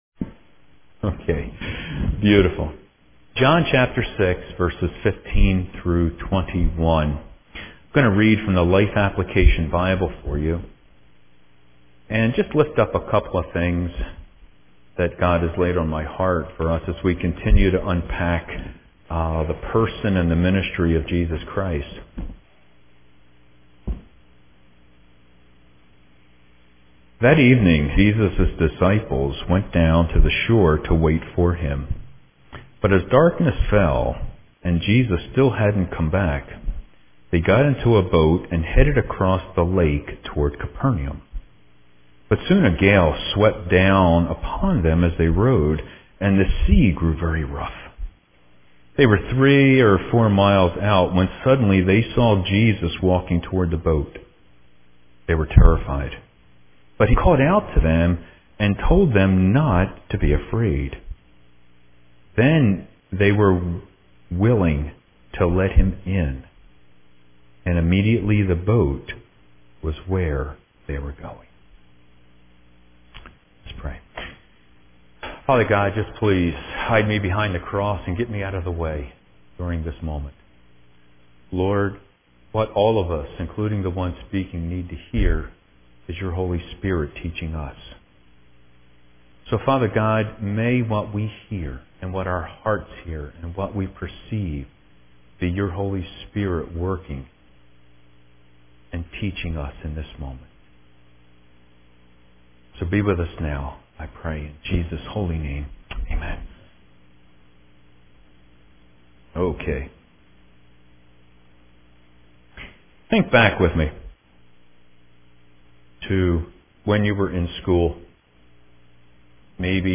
This morning, we looked at Jesus as our Shepherd who protects us through all of life’s storms. A recording of the sermon from the 11AM service is below.